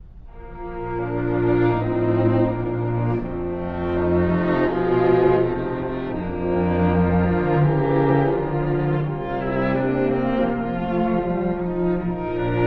↑古い録音のため聴きづらいかもしれません！（以下同様）
Andante con moto
朗々と歌われる緩徐楽章です。冒頭は2ndバイオリンのメロディーから始まります。
始まりはB-dur。そこから何回も転調し、中間部ではどんどんフラットが増えていきます。
この曲は全体的に爽やかなイメージですが、2楽章に関しては暖かい暖炉のような感覚を持つかもしれません。
beethoven-sq3-2.mp3